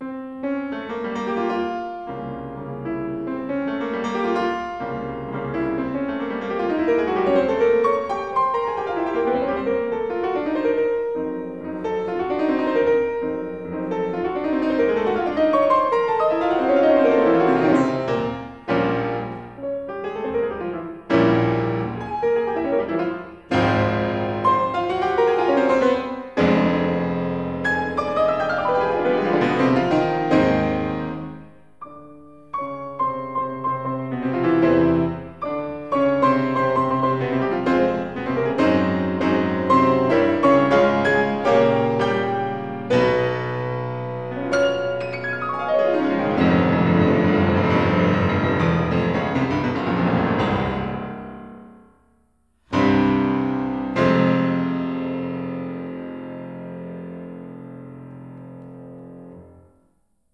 The analyzed Chopin performances were audio recordings played by 5 famous pianists:
Grigory Sokolov recorded in 1990